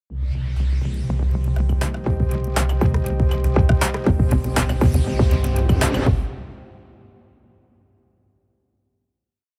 Download Podcast Intro sound effect for free.
Podcast Intro